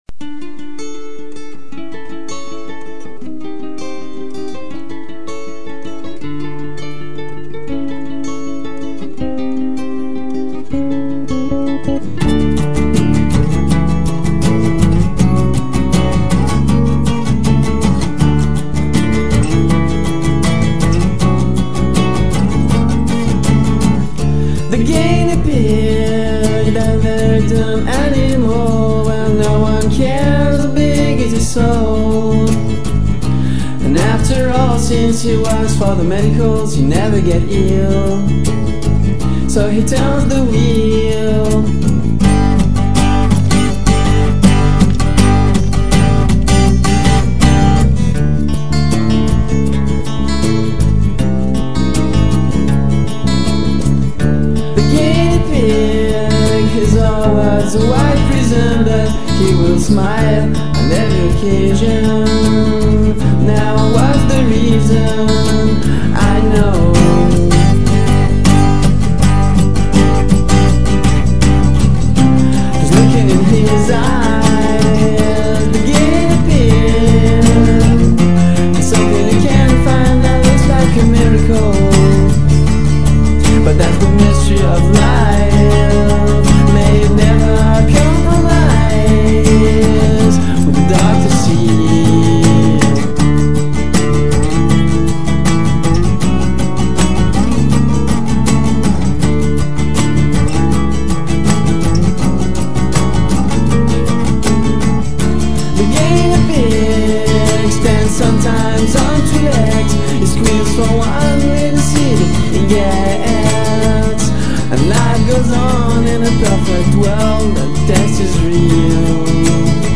Acoustique